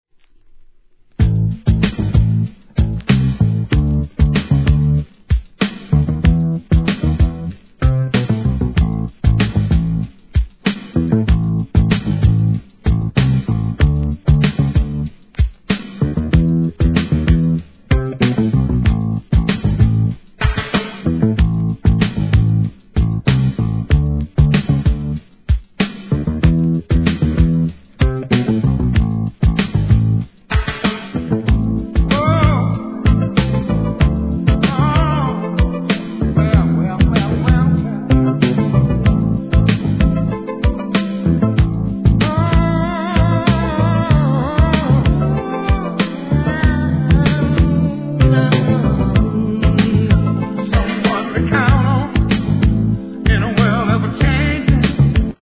SOUL/FUNK/etc...
このベースがまたかっこいいんですよね♪